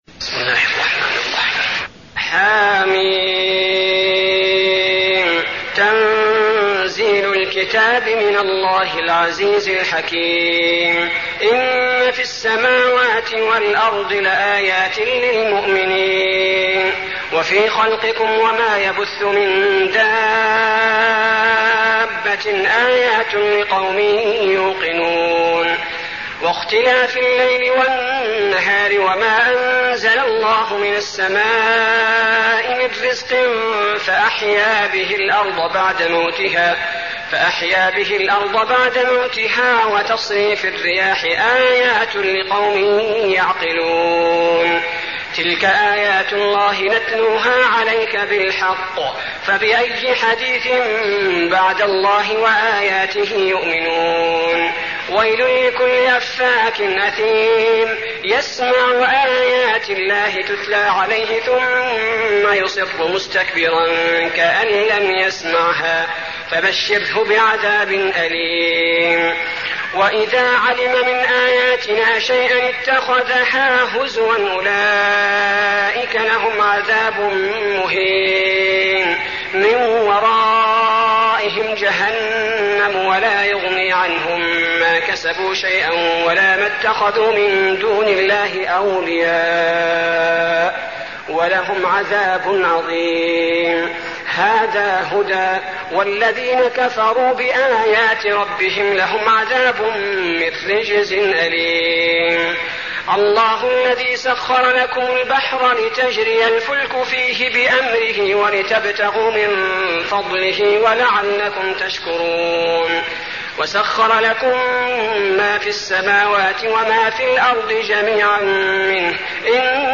المكان: المسجد النبوي الجاثية